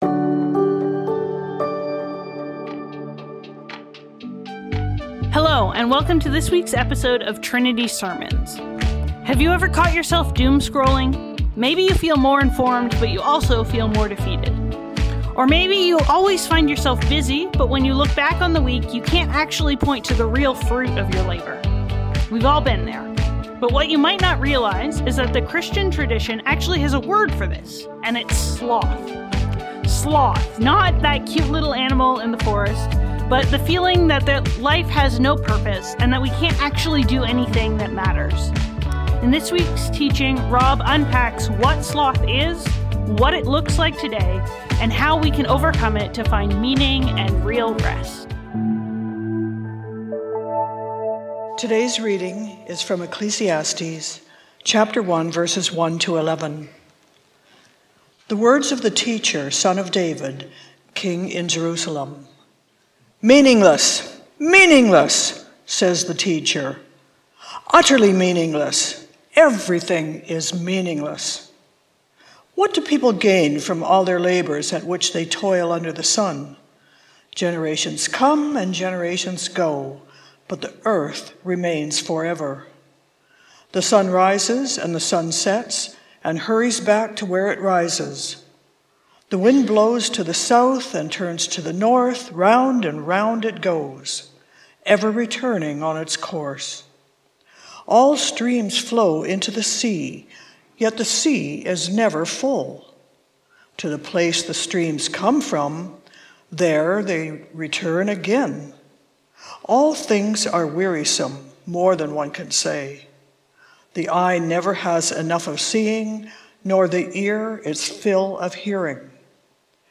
Trinity Streetsville - Shaking Off Sloth | The "S" Word | Trinity Sermons - Archive FM